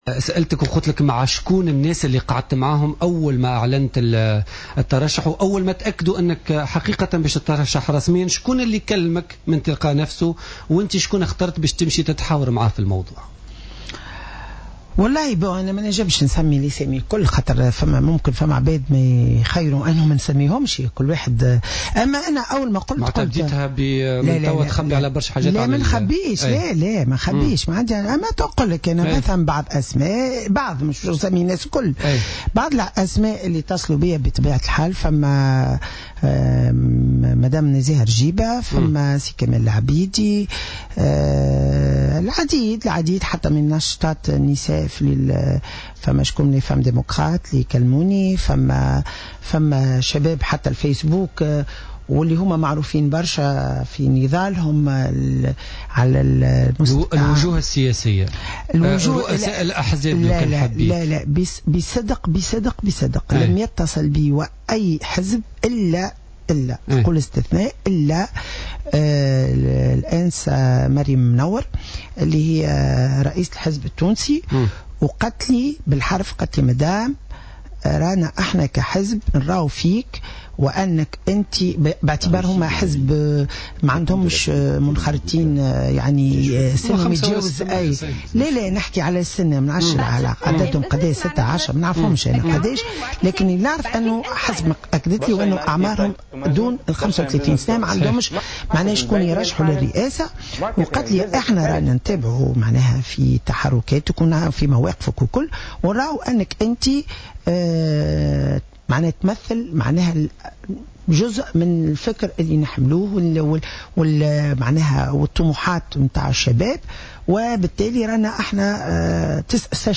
أكدت كلثوم كنو اليوم الاربعاء خلال مداخلة لها في برنامج "بوليتيكا" أن عديد الأسماء المعروفة اتصلت بها إثر إعلان ترشحها للانتخابات الرئاسية على غرار نزيهة رجيبة وكمال العبيدي.